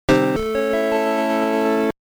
Alors voila, le cri de défaite !
mp3_deathchime.mp3